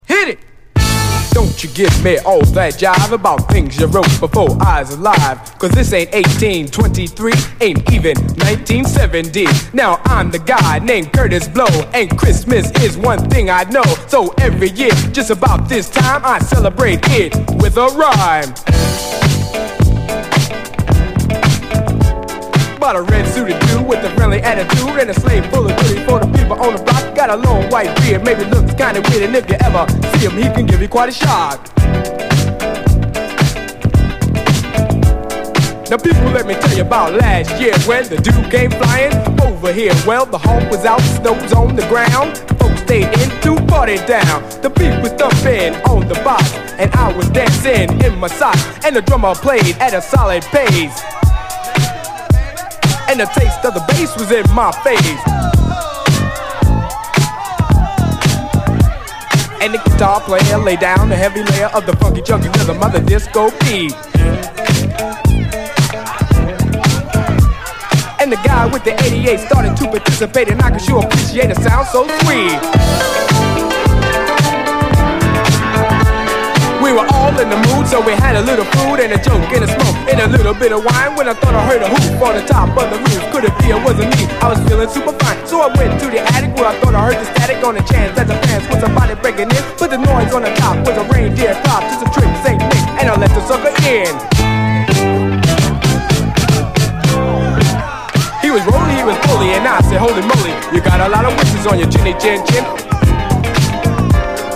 SOUL, 70's～ SOUL, DISCO, HIPHOP, 7INCH
オールドスクール・ディスコ・ラップの記念碑的一曲！
盤見た目VG+なのでディスカウント・プライスですが、A面はまずまずPLAY OK。